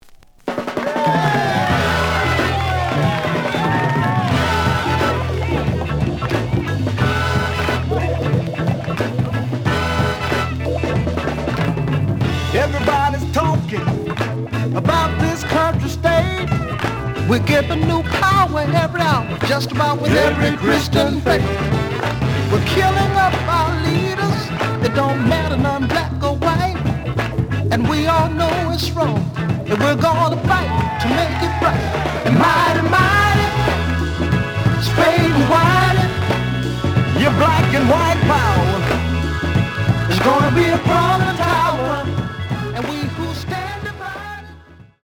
The audio sample is recorded from the actual item.
●Genre: Soul, 60's Soul